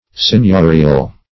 Meaning of seigniorial. seigniorial synonyms, pronunciation, spelling and more from Free Dictionary.
Seigniorial \Seign*io"ri*al\, a.